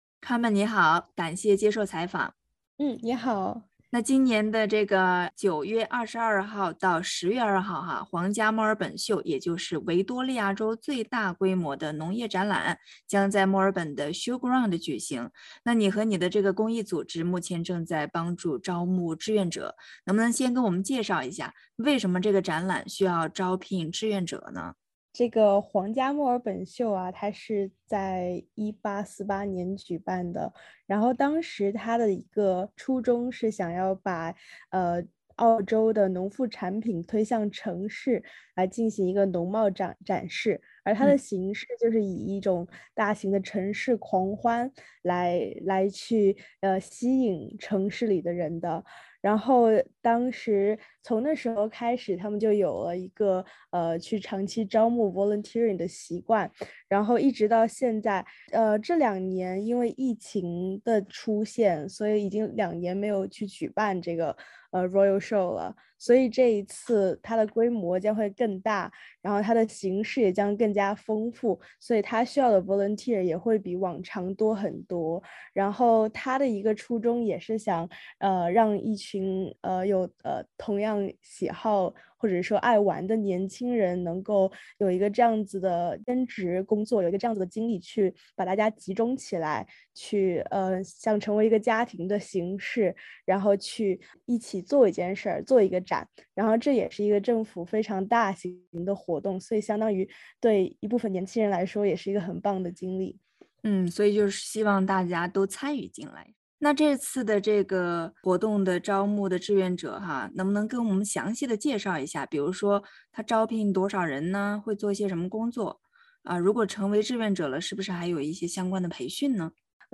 想了解SJ青橙空间站自己组织的活动，请点击上方音频收听完整采访！